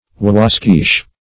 Search Result for " wawaskeesh" : The Collaborative International Dictionary of English v.0.48: Wawaskeesh \Wa*was"keesh\, n. [From an Indian name.]